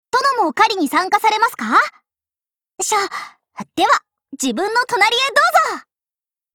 贡献 ） 协议：Copyright，人物： 碧蓝航线:龙骧语音 您不可以覆盖此文件。